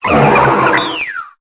Cri de Cryptéro dans Pokémon Épée et Bouclier.